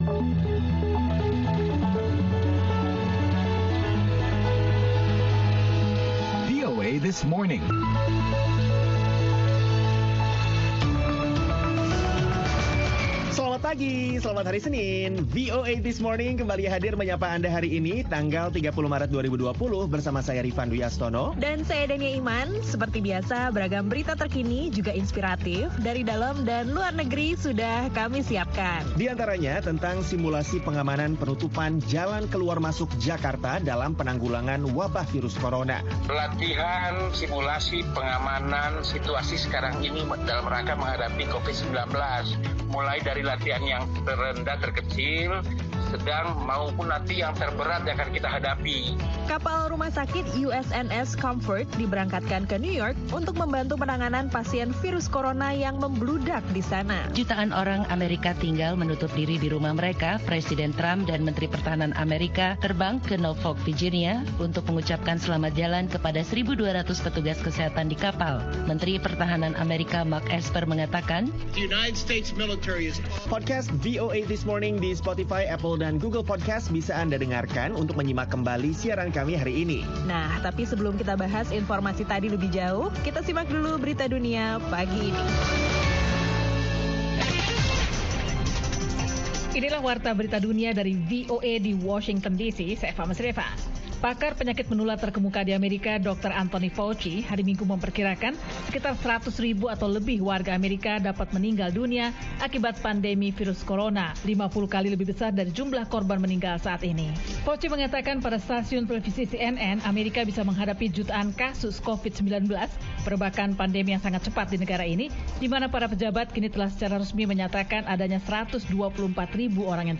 langsung dari Washington, D.C.